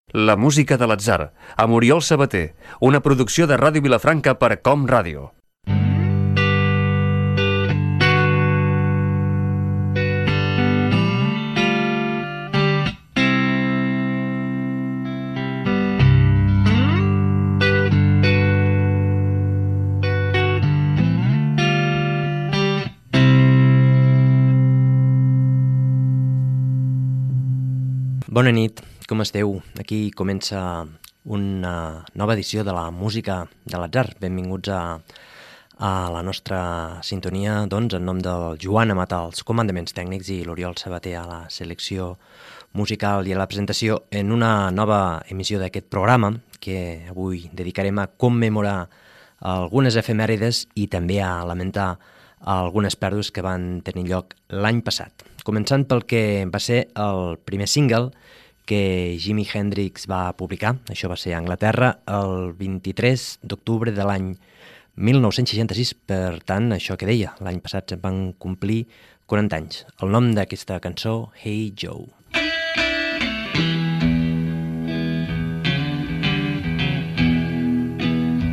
Careta i presentació inicial del programa
Musical